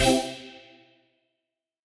FR_T-PAD[hit]-C.wav